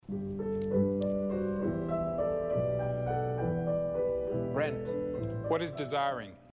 BACH'S CANTATAS
The title of the cantata melody heard